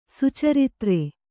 ♪ sucaritre